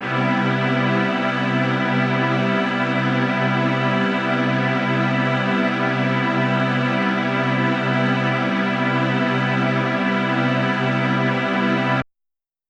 SO_KTron-Ensemble-Cmaj7.wav